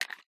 9mm M&P Drop Mag Empty C.wav